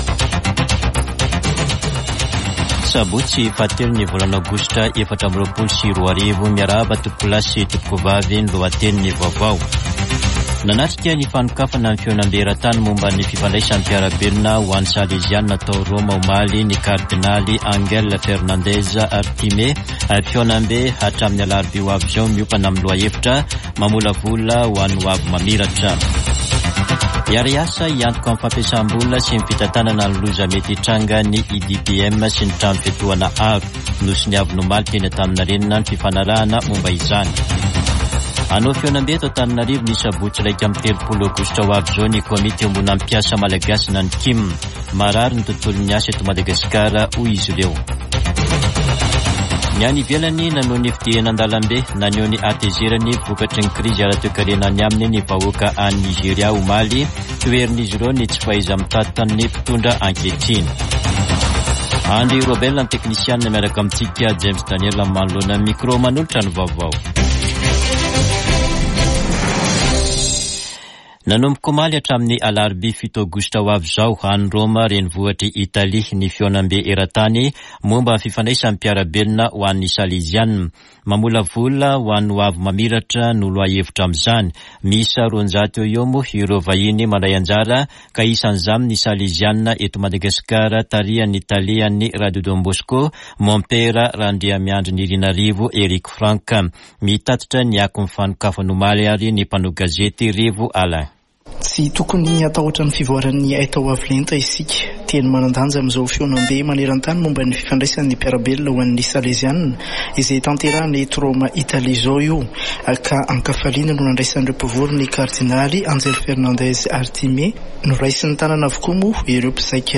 [Vaovao maraina] Sabotsy 3 aogositra 2024